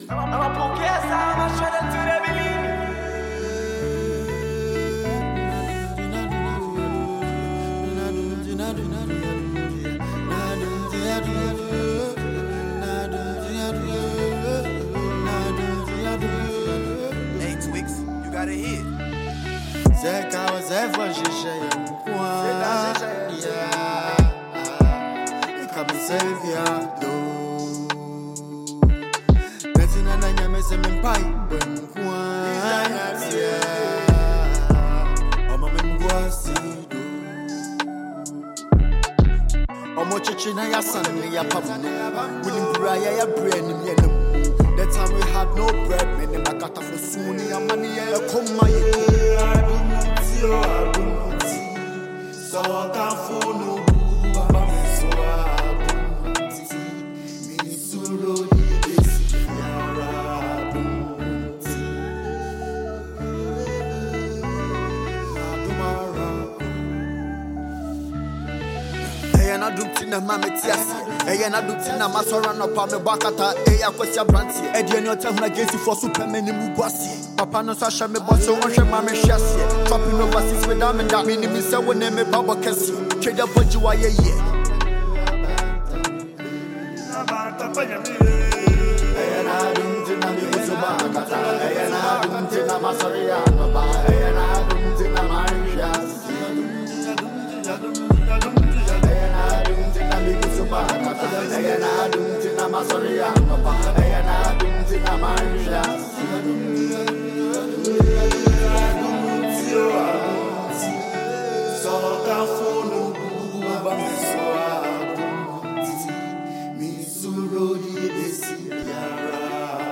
Asakaa rapper